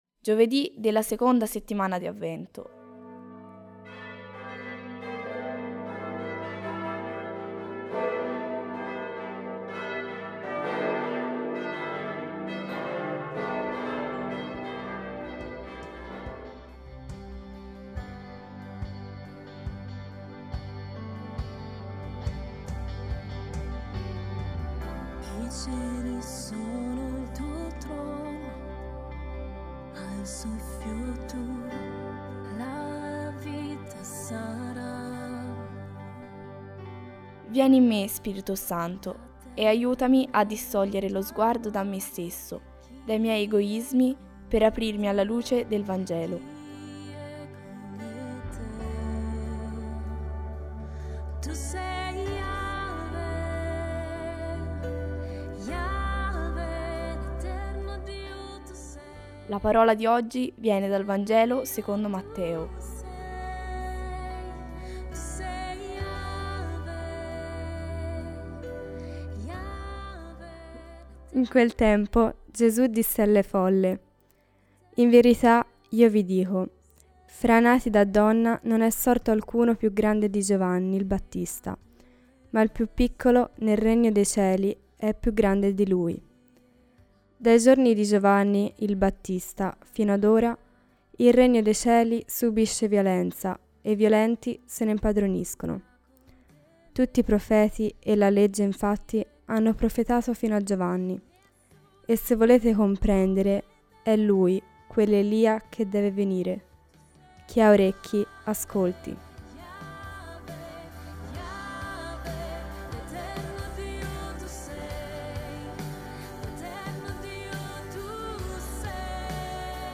Musica di Rehoboth Music: Yahweh